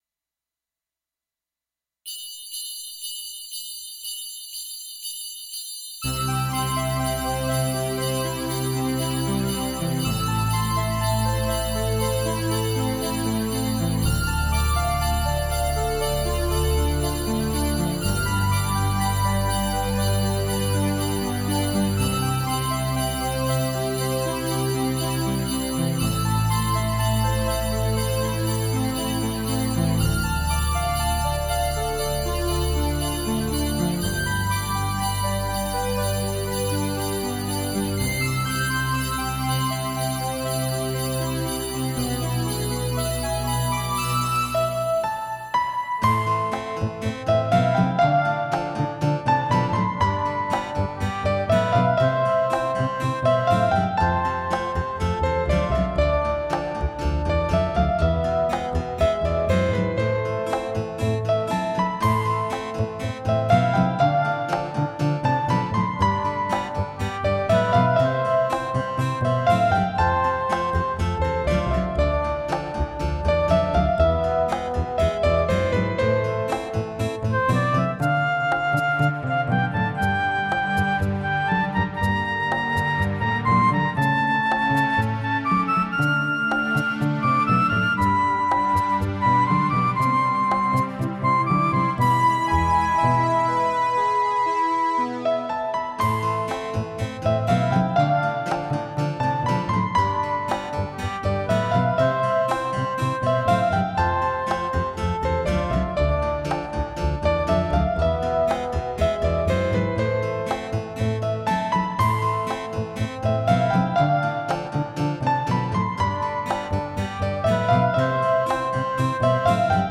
曲のコード進行も似ています。